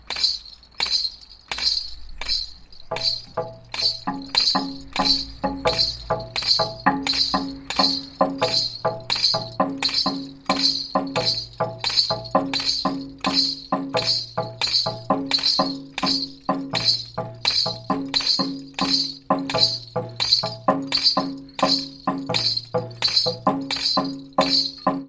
Die Sechsachtel Clave .
Eine rhythmische Grundfigur aus Westafrika. Schellen und Shaker an den Füßen markieren Anfang und Mitte des Taktes.